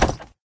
woodstone.ogg